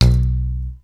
28. 28. Percussive FX 27 ZG